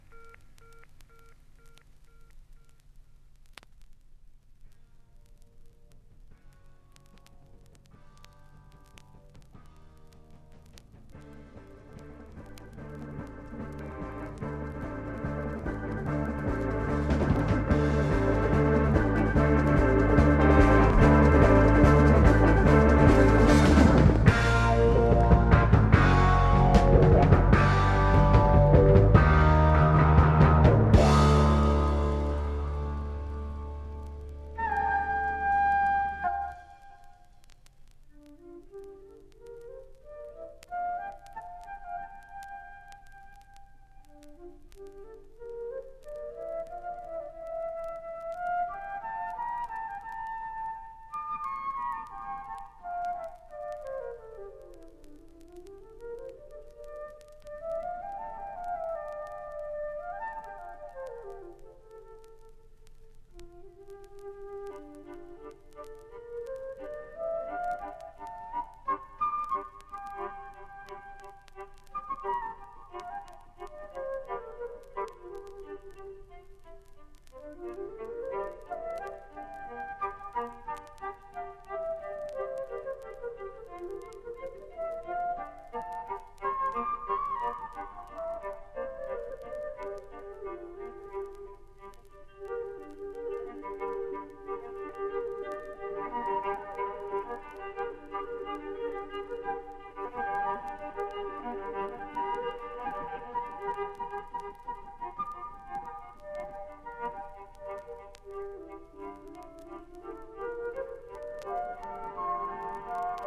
Jazz influenced Progressive rock approach